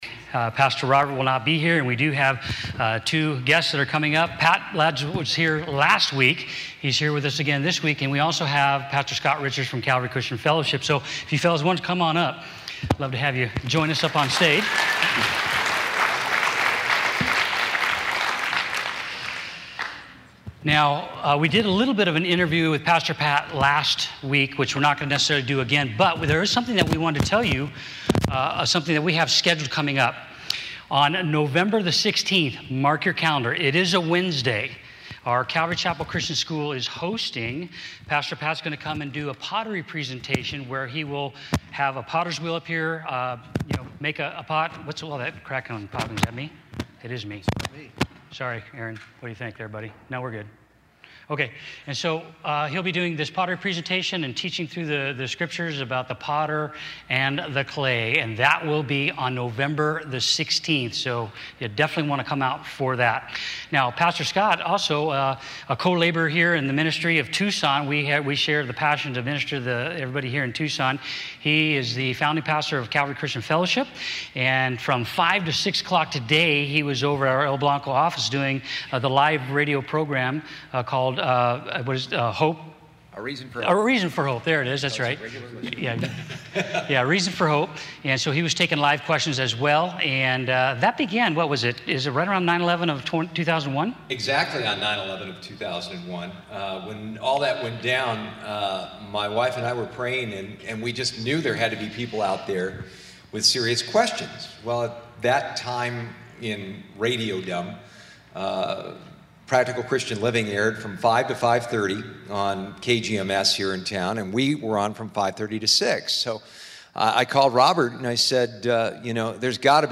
2016 · Multiple Listen to a pannel of our pastors give their answers for questions submitted to the Inquire Q&A series.